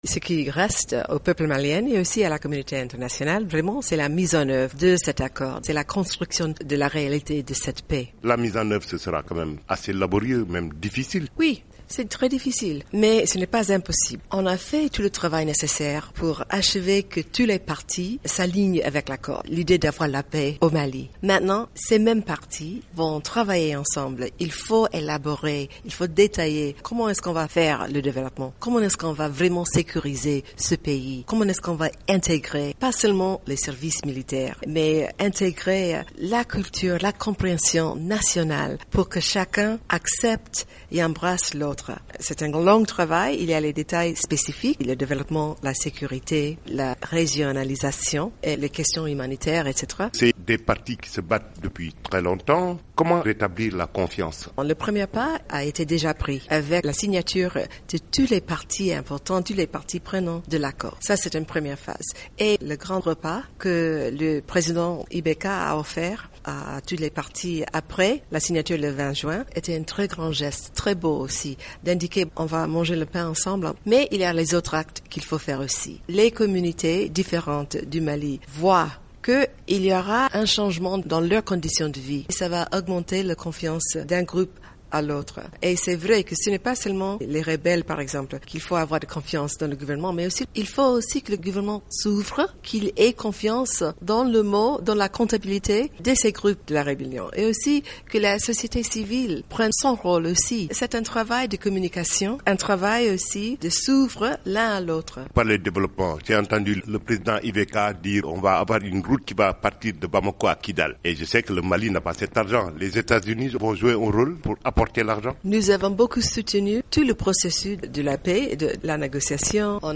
Bisa Williams, sous-secrétaire d'Etat aux Affaires africaines